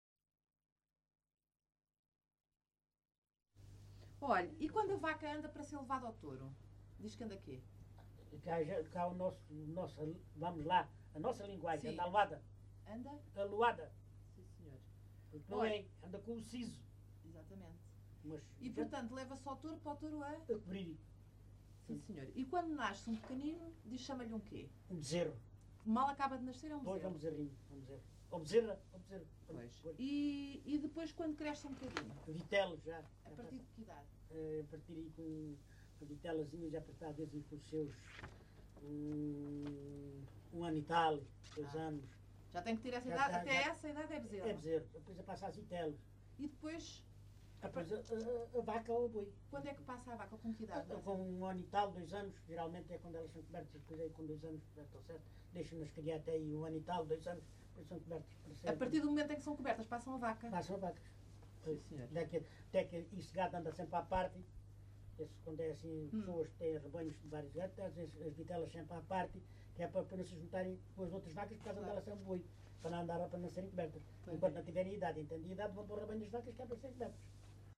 LocalidadeLavre (Montemor-o-Novo, Évora)